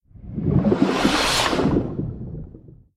Огромный осьминог проплыл вблизи под водой